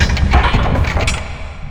c_spiker_dead.wav